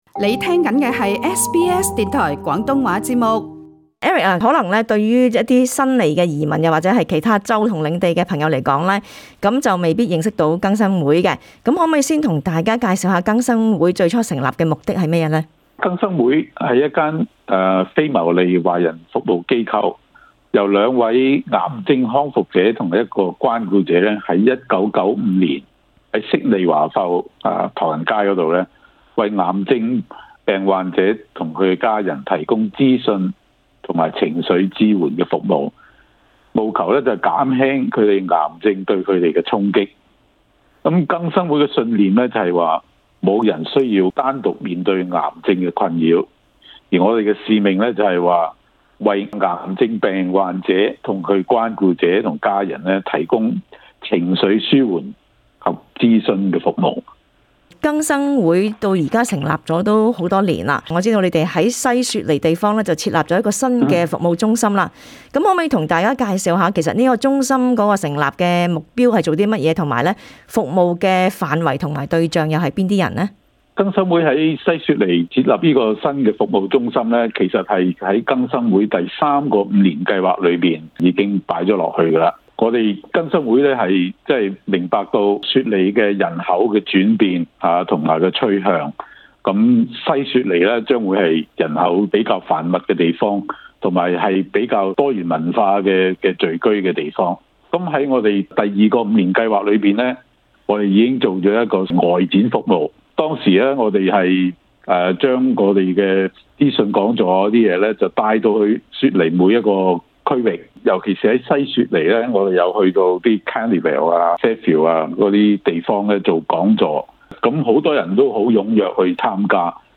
【社区专访】